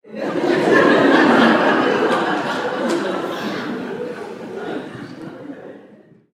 دانلود صدای خنده دسته جمعی 1 از ساعد نیوز با لینک مستقیم و کیفیت بالا
جلوه های صوتی
برچسب: دانلود آهنگ های افکت صوتی انسان و موجودات زنده